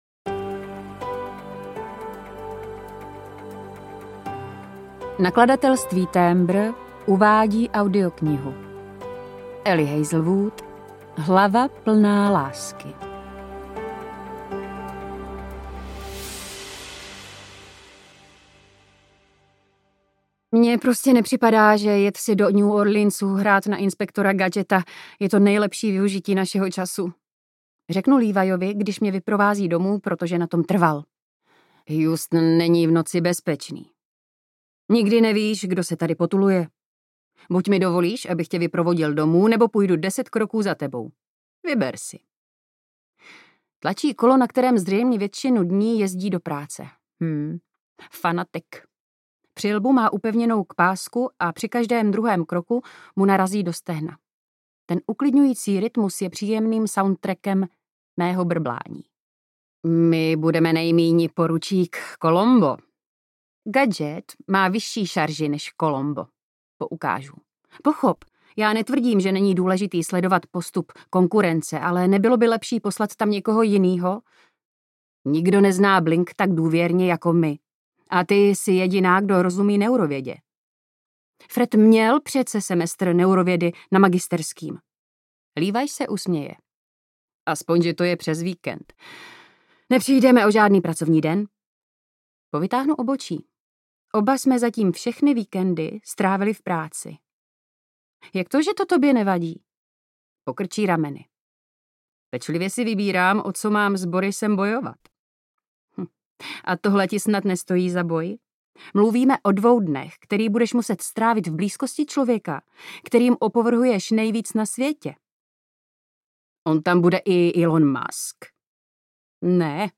Hlava plná lásky audiokniha
Ukázka z knihy